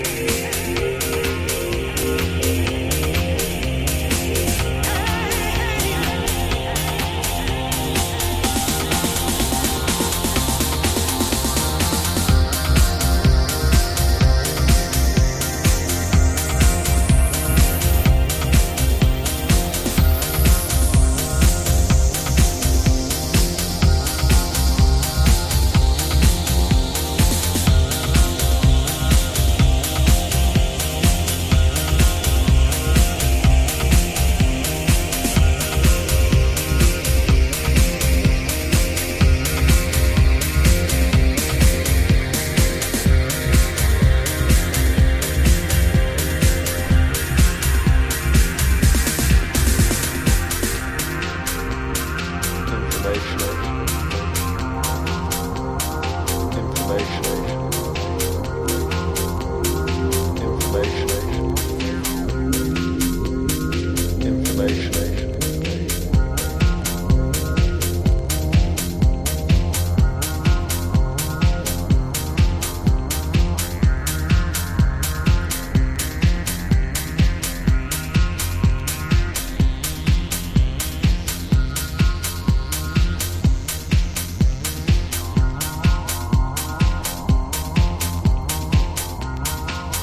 レイヤードされたコズミック・シンセとトランシーな展開が◎なエレクトリック・ハウス！
DEEP HOUSE / EARLY HOUSE